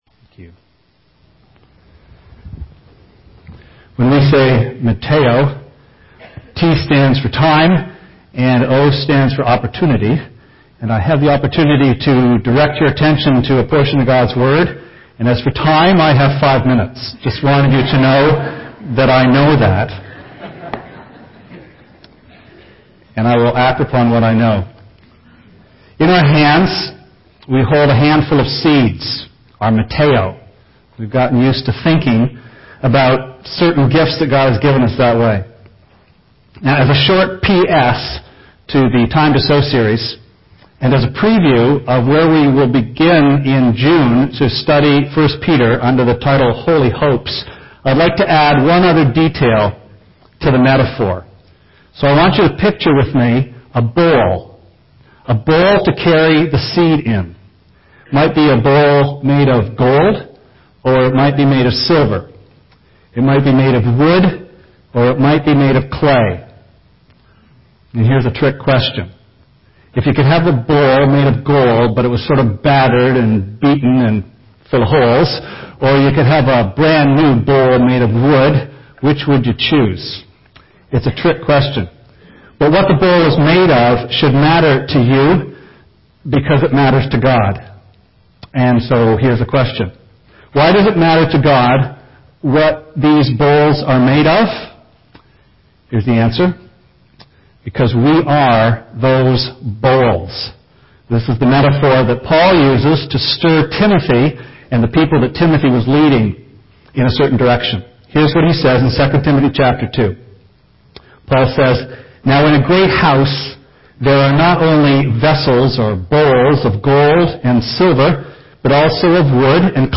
Sermon Archives
Paul the apostle's call to Timothy to personal holiness as a means of becoming "useful to the Master, ready for every good work." This very brief sermon is a Post Script to the "Time to Sow" series.